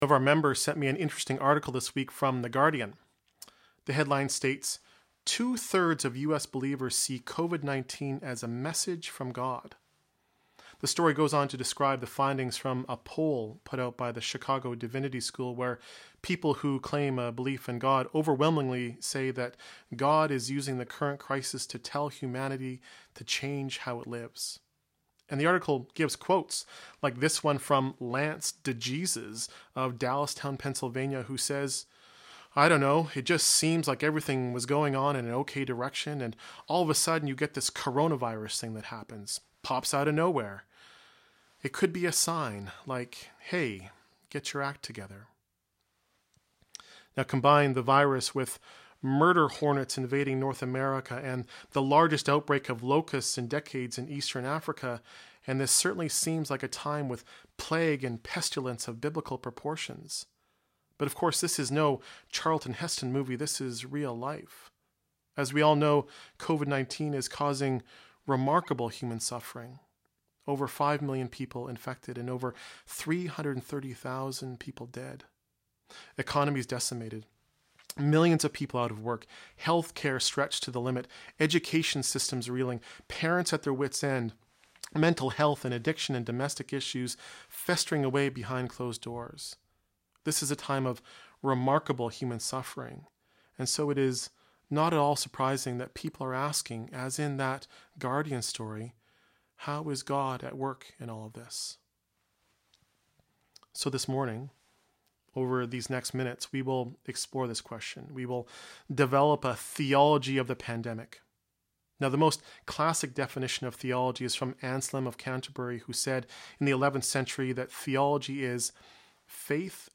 Sermons & Livestreams | Metropolitan United Church